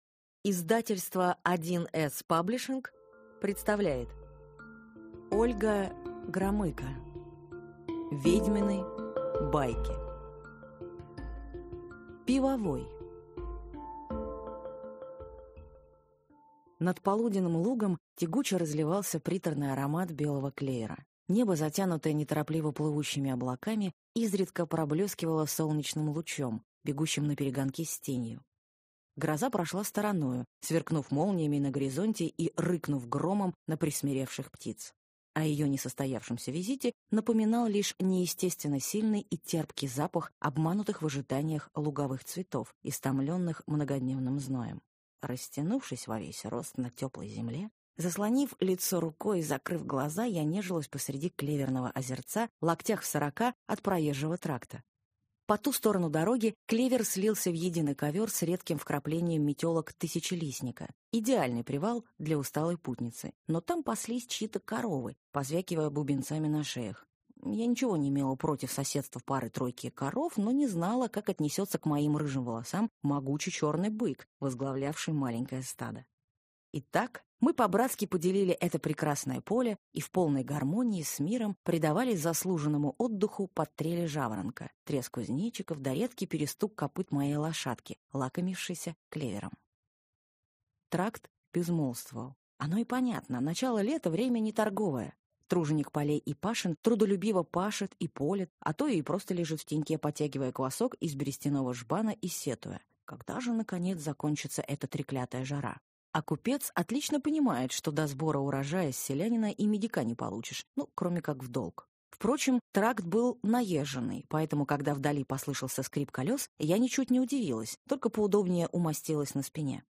Аудиокнига «Ведьмины байки» в интернет-магазине КнигоПоиск ✅ в аудиоформате ✅ Скачать Ведьмины байки в mp3 или слушать онлайн